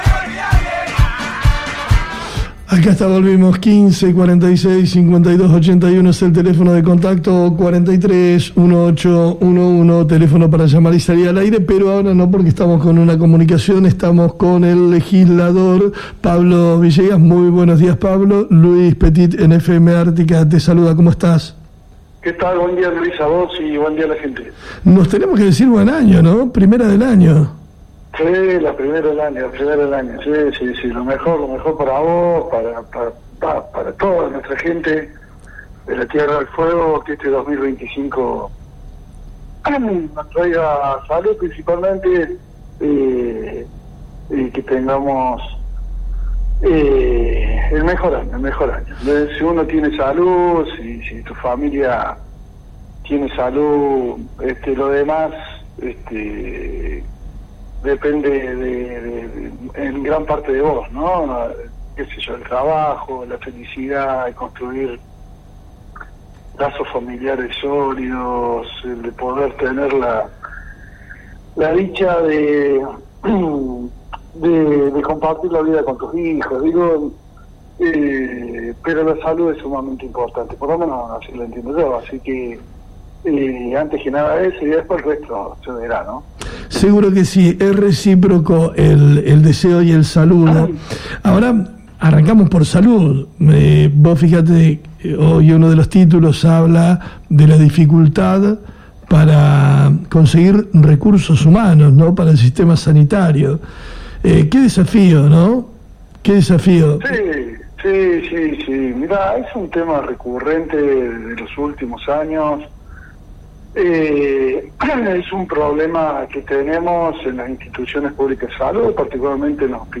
Entrevistamos en FM Ártika, al Legislador Provincial por el MOPOF, Pablo Villegas.